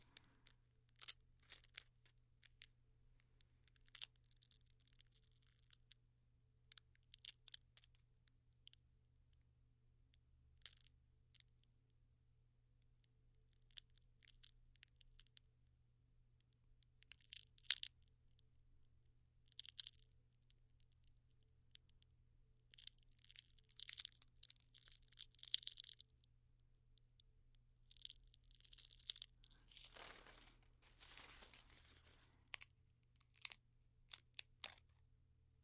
干燥的叶子 孤立的噼啪声
描述：在工作室操纵干燥的叶子。设备+骑行方向+ 5增益
Tag: 噼啪 裂纹 分离的 紧缩 干燥